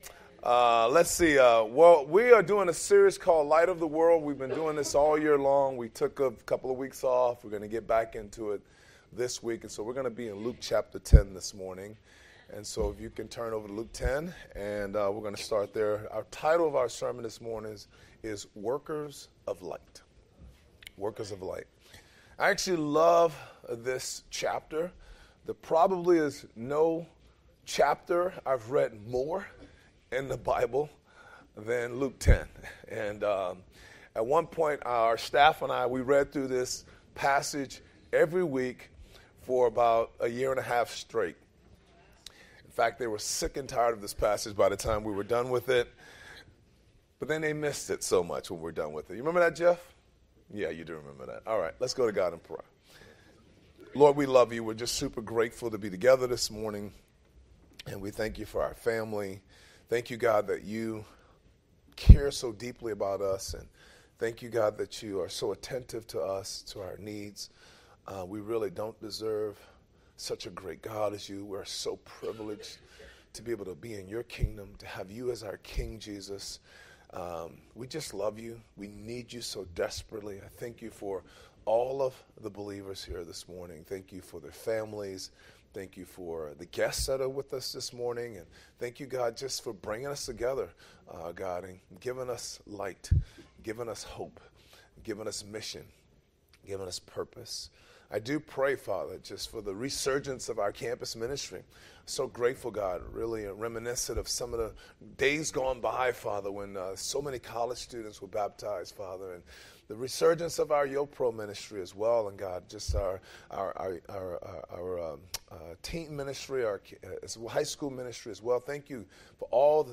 Sermons | Gateway City Church